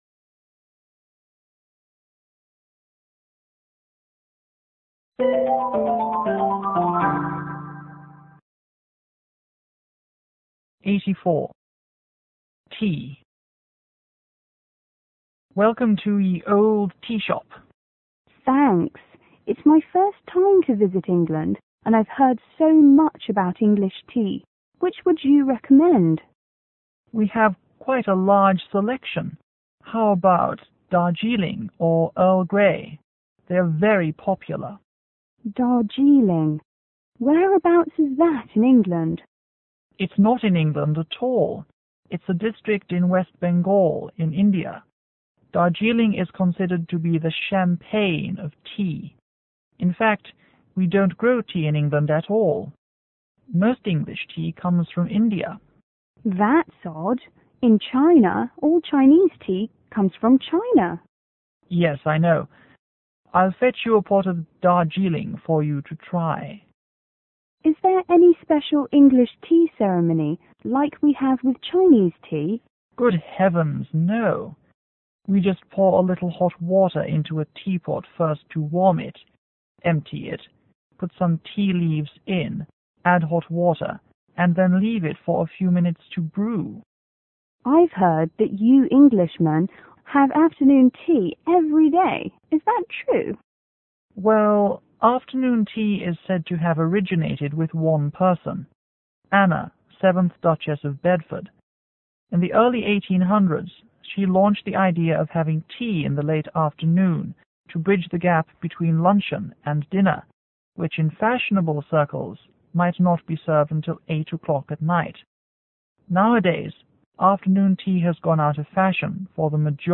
O: Tea shop owner      T: Tourist